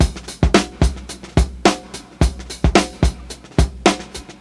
• 109 Bpm 00's Drum Loop C Key.wav
Free drum loop - kick tuned to the C note.
109-bpm-00s-drum-loop-c-key-MMD.wav